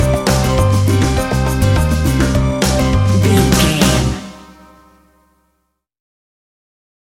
An exotic and colorful piece of Espanic and Latin music.
Aeolian/Minor
flamenco
groove
maracas
percussion spanish guitar